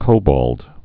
(kōbôld)